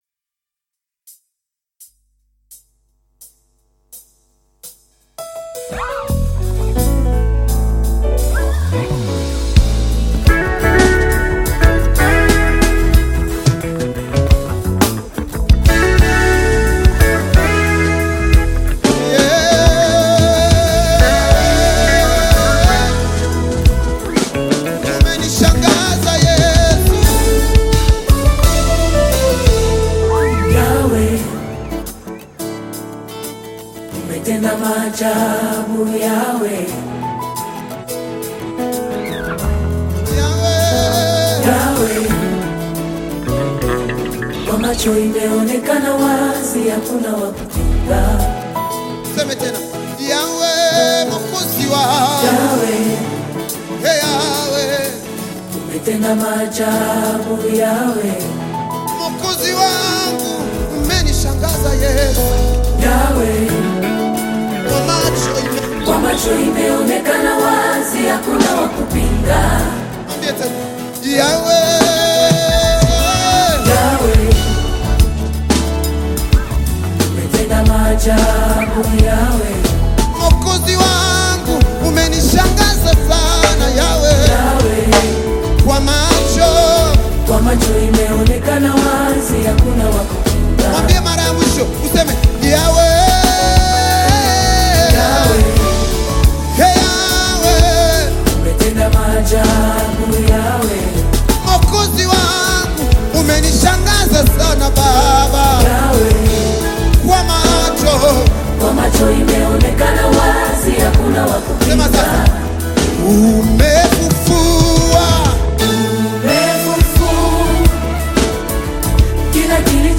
Gospel music track
Tanzanian Gospel